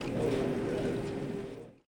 Ambient1.ogg